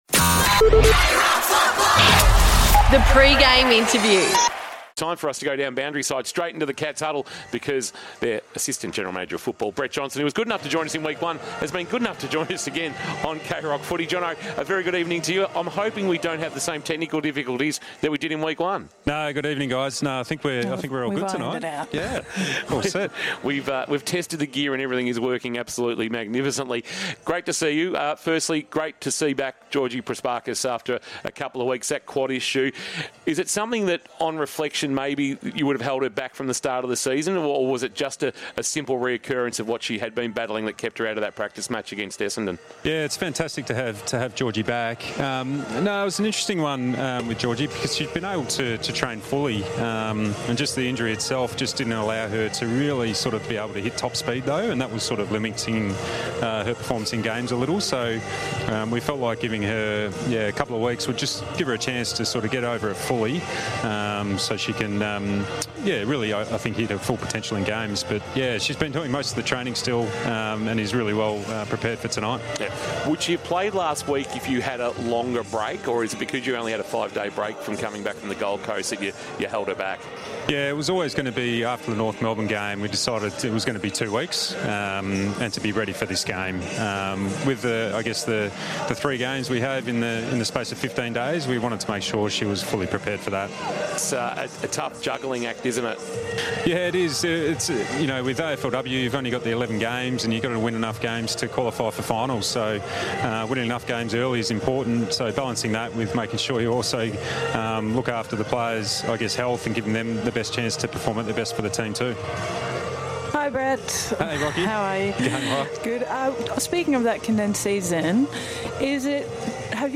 2024 - AFLW - Round 5 - Geelong vs. Hawthorn: Pre-match interview